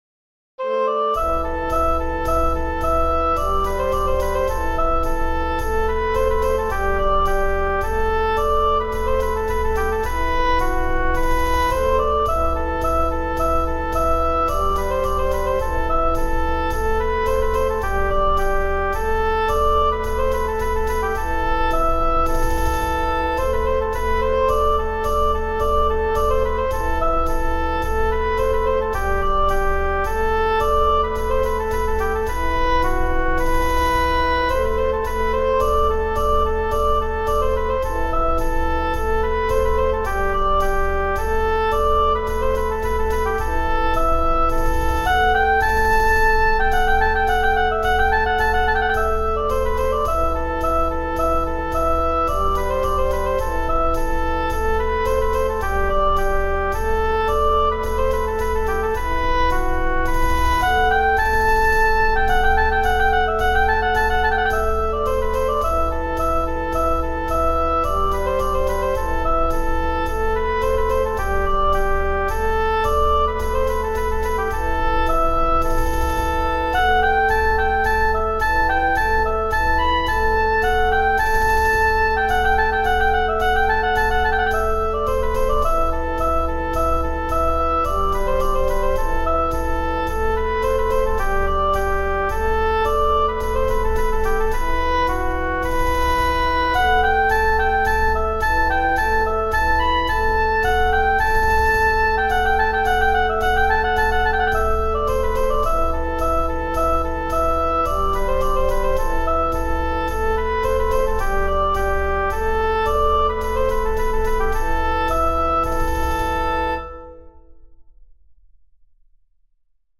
arranged for oboe bassoon and percussion